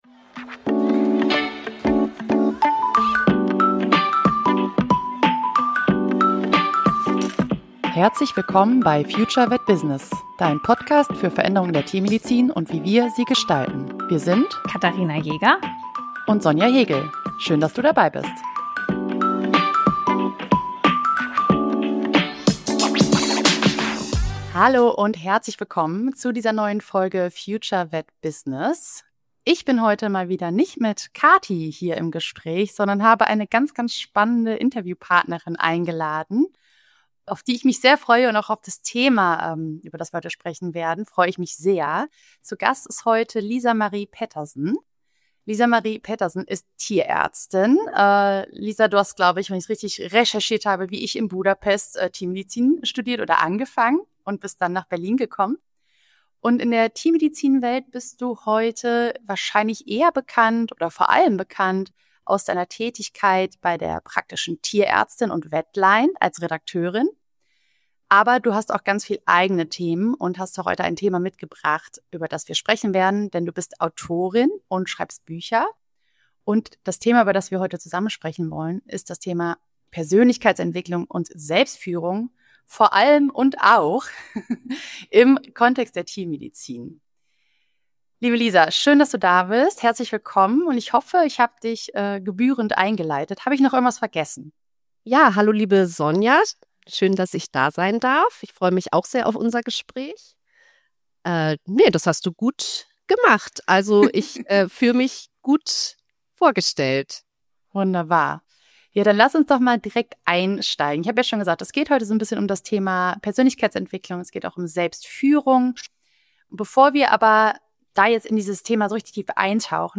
Interview ~ FutureVetBusiness Podcast